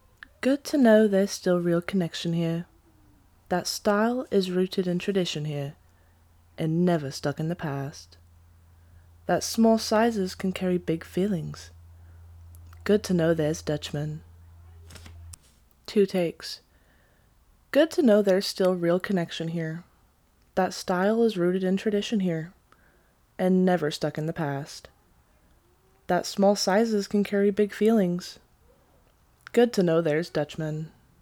Professional Voiceover Artist
Clear, confident, and high-quality voiceovers for brands, content creators, and businesses.